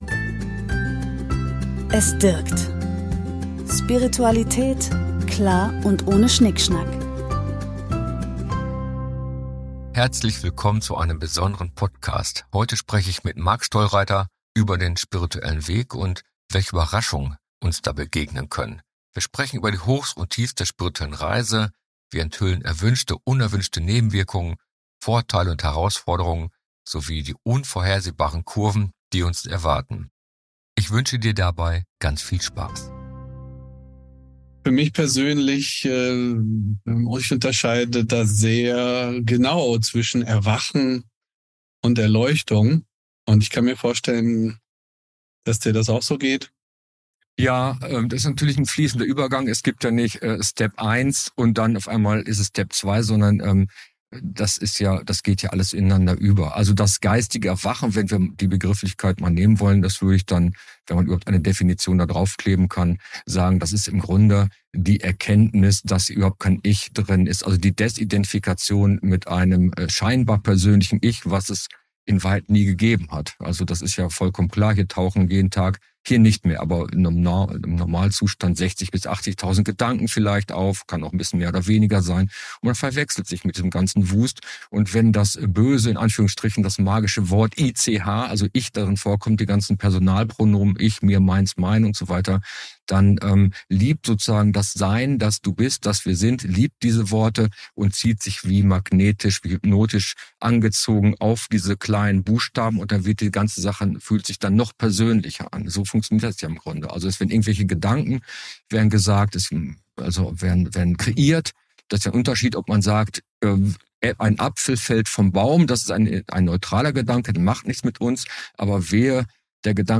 Interview: Nebenwirkungen des Erwachens – Was dir niemand vorher erzählt ~ Du schöner Mensch!